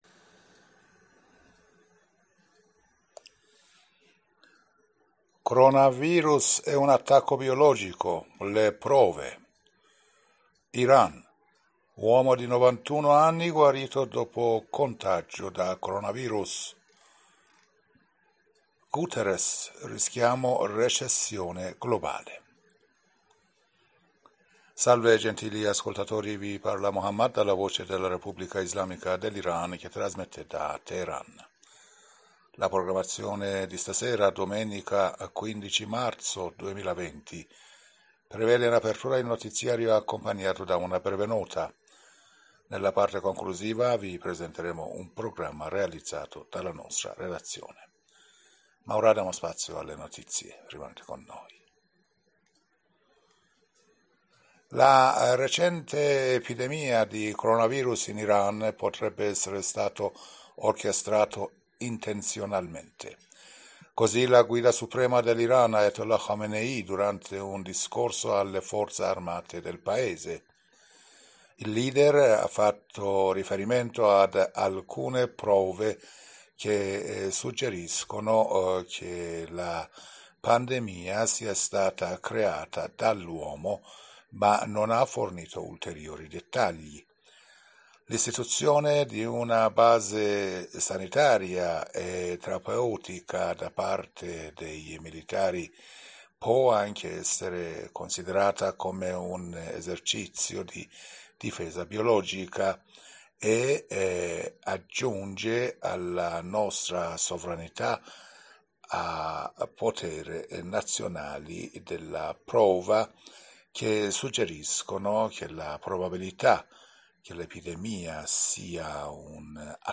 Giornale radio sera 15 marzo